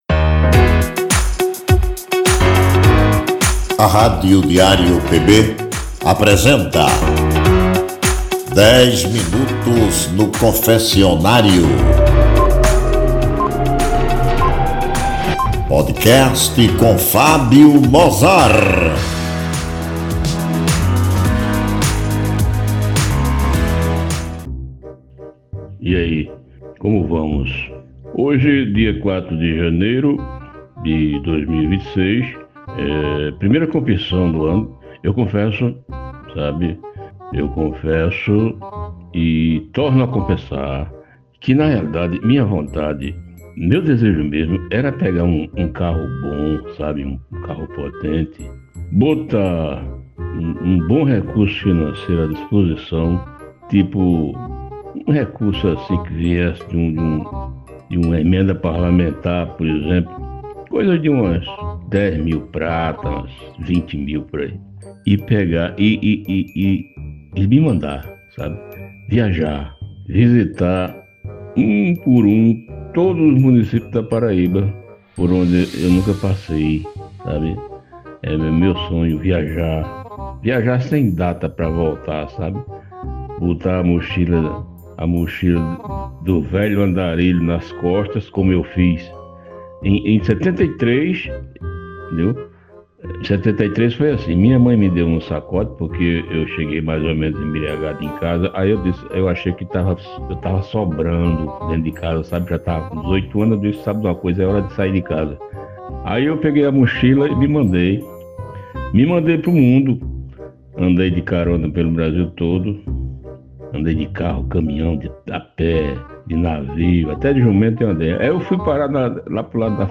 é um programa com um papo descontraído, às vezes incomum, sobre as trivialidades do nosso cotidiano.